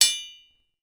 Rock.wav